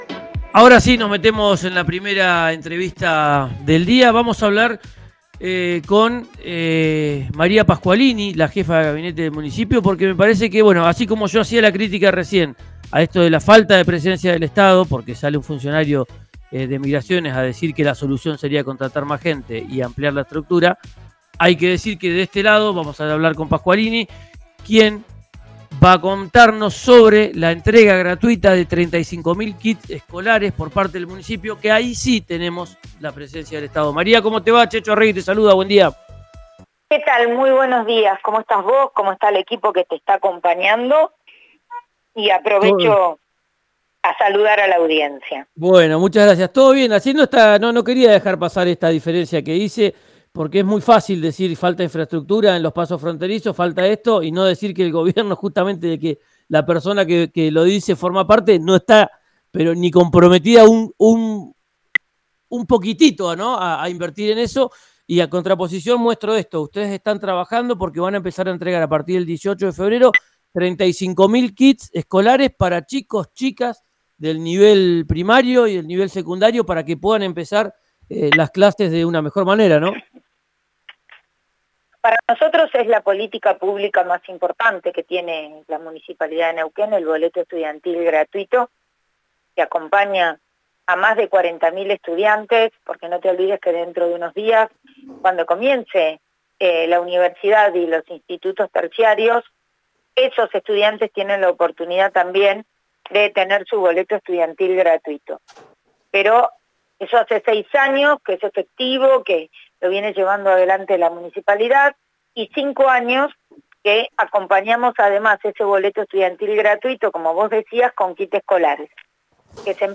Escuchá a María Pasqualini, jefa de gabinete del municipio de Neuquén, en RÍO NEGRO RADIO: